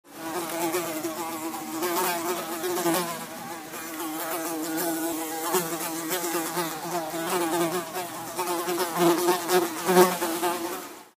Звуки мухи
жужжание мухи звук